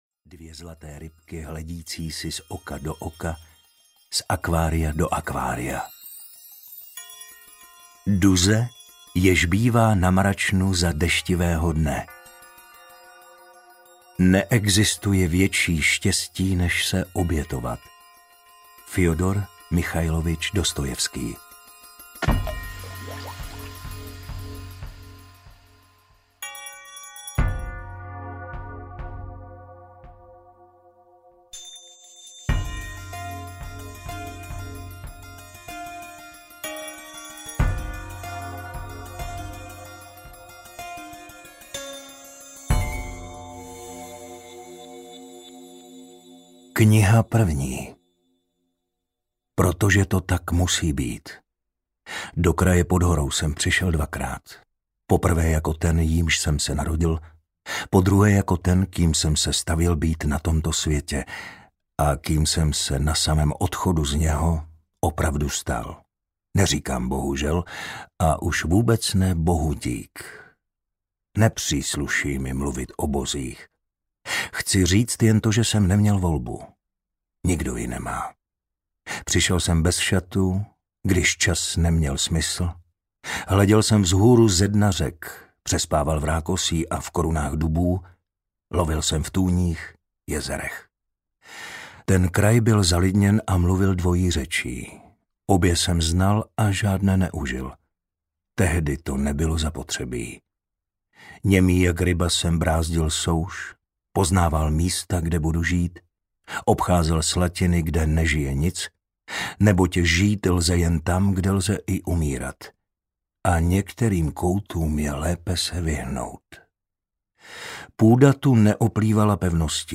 Hastrman audiokniha
Ukázka z knihy